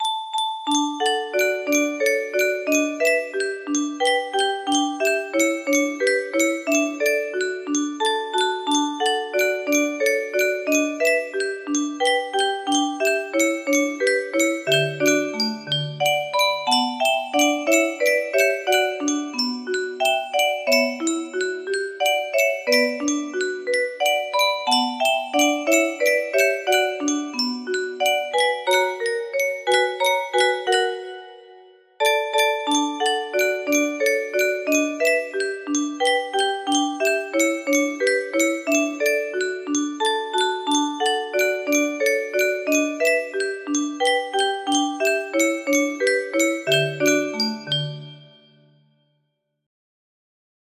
Anónimo - El Huerfanito music box melody
Aire nacional mexicano del estado de Jalisco